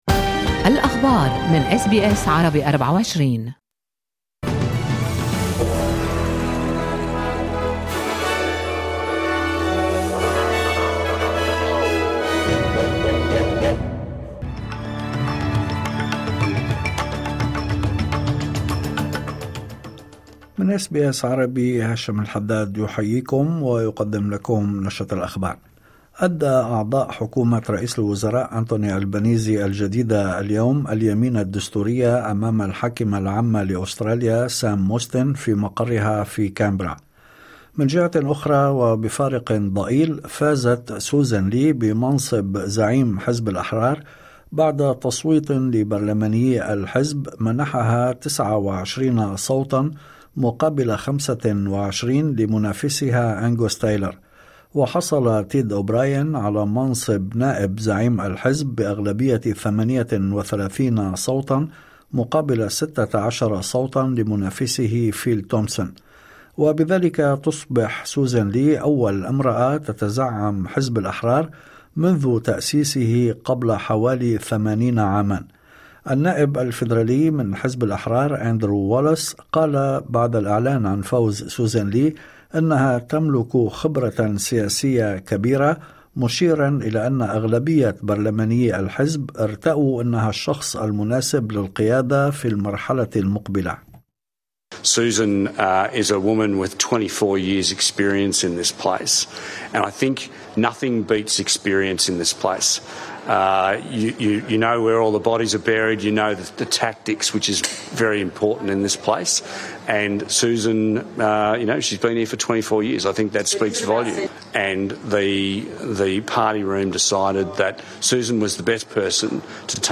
نشرة أخبار الظهيرة 13/05/2025